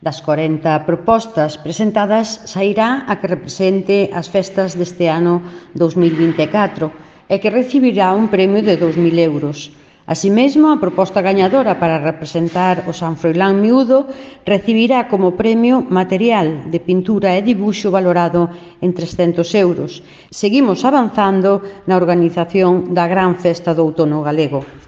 • La concelleira de Cultura, Turismo y Promoción de la Lengua, Maite Ferreiro, sobre la reunión del jurado, para el San Froilán |